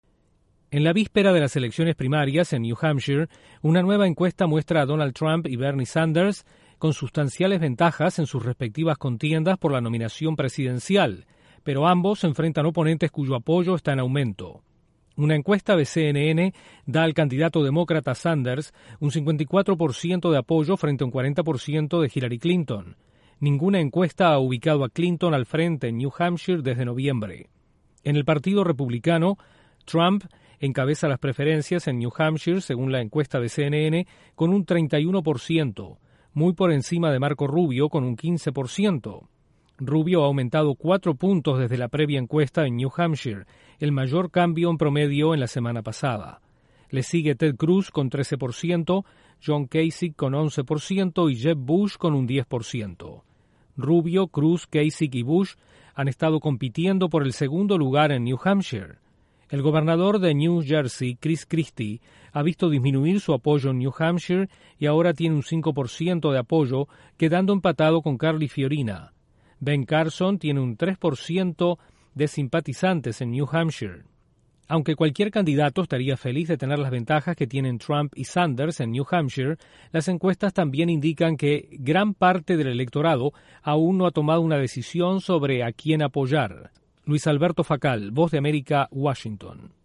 Donald Trump y Bernie Sanders encabezan las encuestas para las elecciones primarias en New Hampshire. Desde la Voz de América en Washington informa